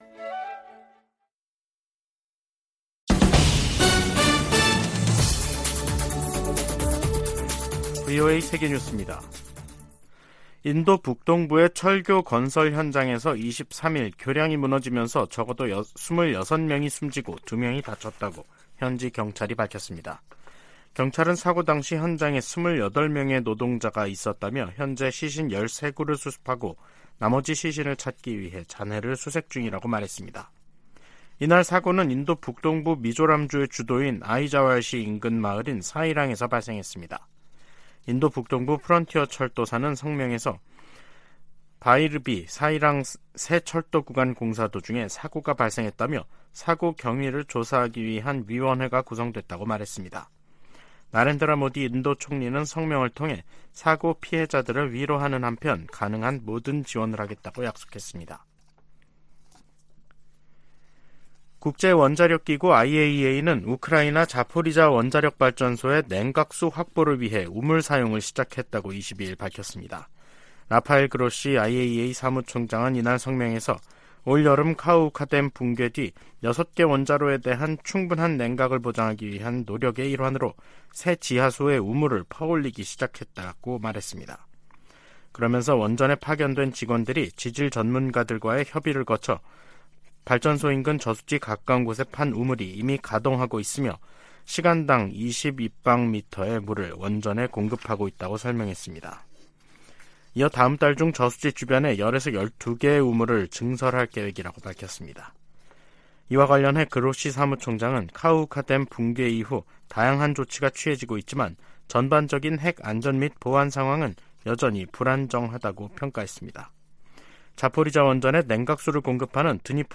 VOA 한국어 간판 뉴스 프로그램 '뉴스 투데이', 2023년 8월 23일 3부 방송입니다. 미 국무부는 탄도미사일 기술이 이용되는 북한의 모든 발사는 유엔 안보리 결의 위반임을 거듭 지적했습니다. 미 국방부가 북한의 위성 발사 통보와 관련해 한국·일본과 긴밀히 협력해 대응하겠다고 밝혔습니다. 미국 정부가 미국인의 북한 여행을 금지하는 조치를 또다시 연장했습니다.